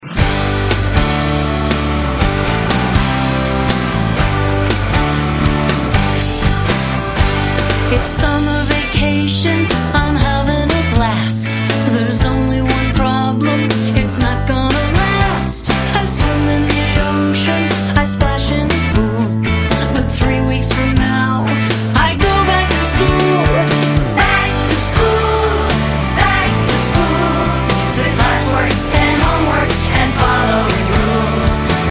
Listen to a sample of this vocal song.